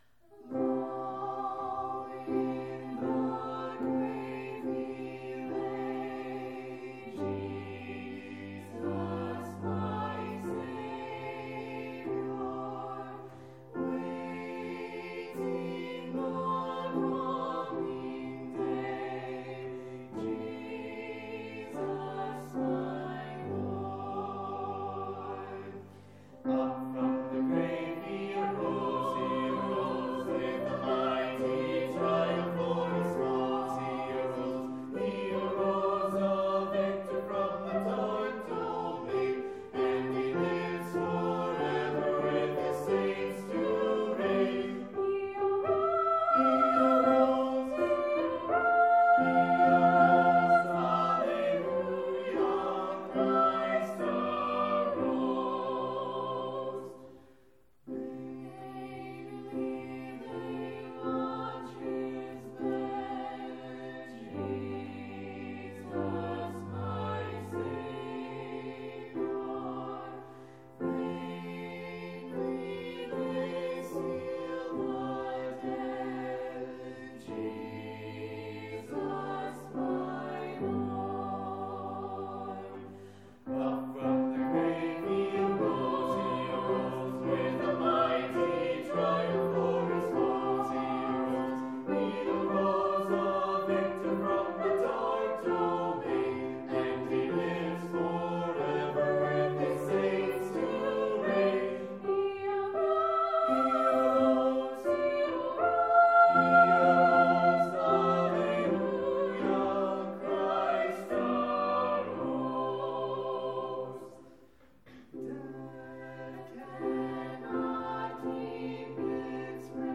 Soprano
Alto
Tenor
Piano
Easter Hymns
Easter-Hymns.mp3